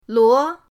luo2.mp3